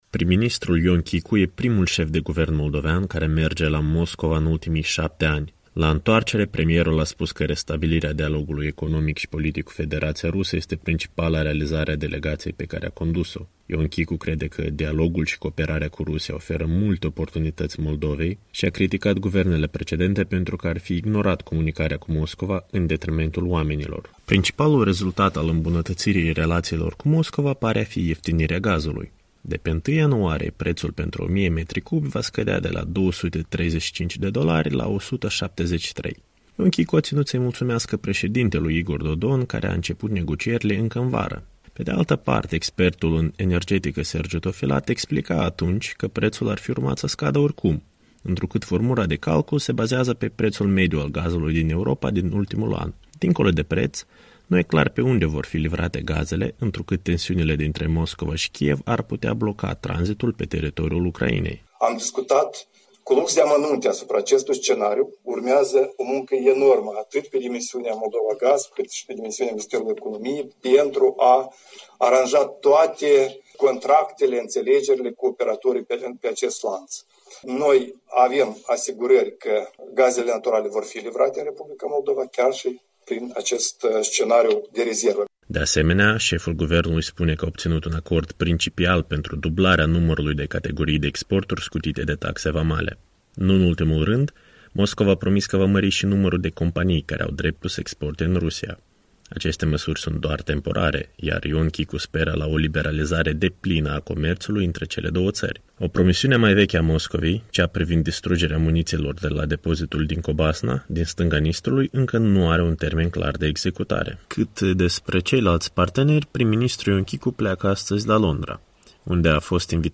Declarațiile premierului Ion Chicu la revenriea de la Moscova, 20 noiembrie 2019
„Aduc mulțumiri președintelui R. Moldova, dlui Igor Dodon, care a lansat în august dialogul cu partea rusă în sensul diminuării prețului de import a gazelo” a declarat miercuri seară Chicu, la conferința de presă de la aeroportul Chișinău.